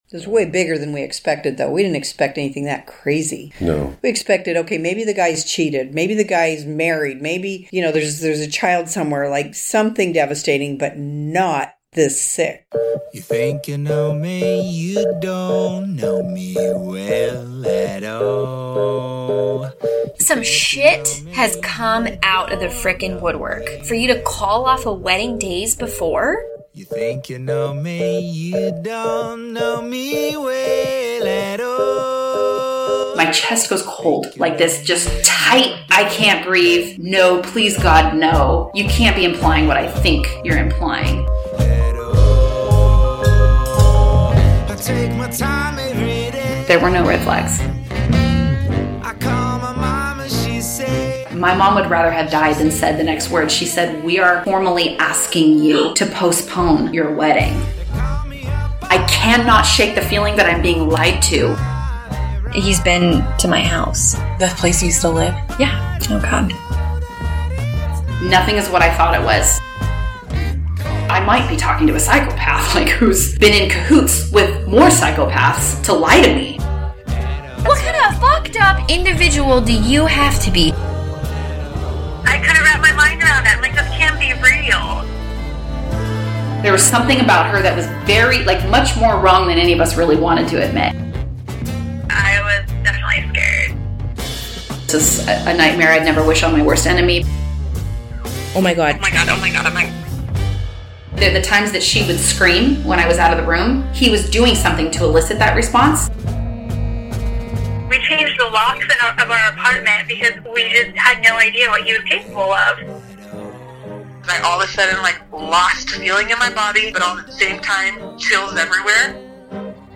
A docuseries podcast about the discovery, trauma and recovery of being engaged to a sociopath.